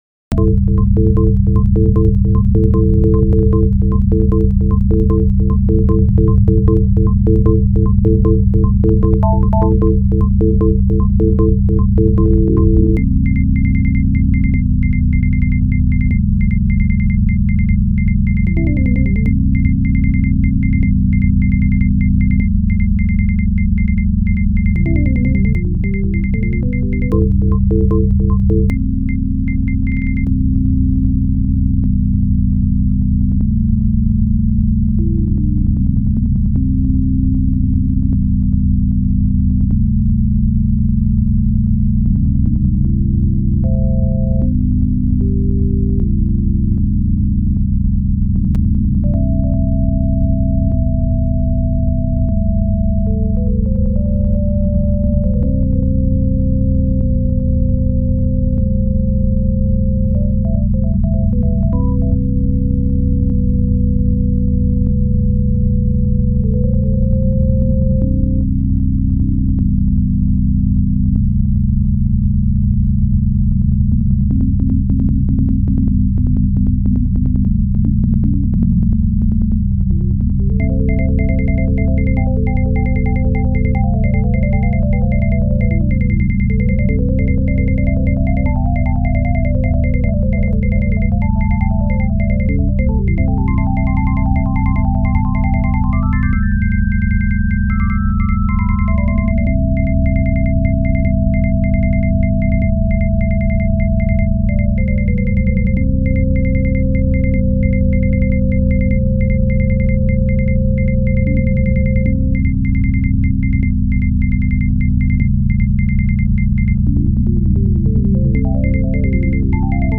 ・サイン波が出せるオペレータが一つ
・ADSRで音量をコントロール
v1.0でMDXデータを鳴らしてみました。
サイン波ならではの味わいがありますね。